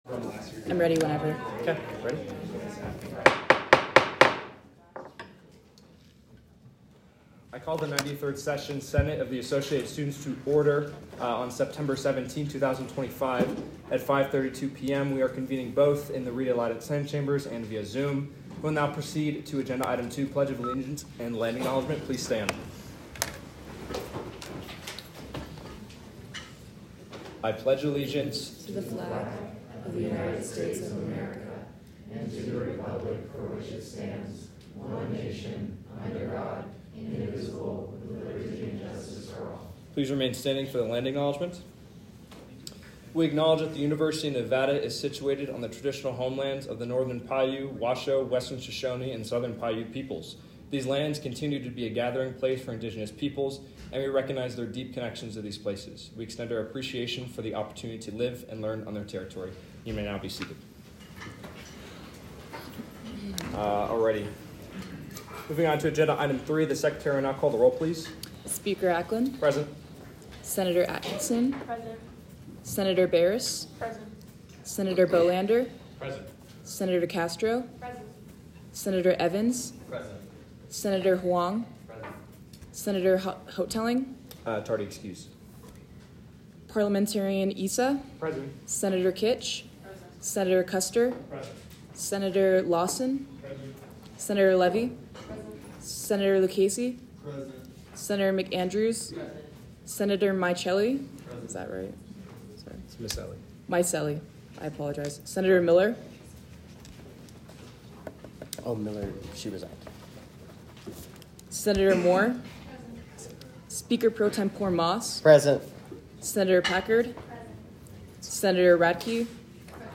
Location : Rita Laden Senate Chambers
Audio Minutes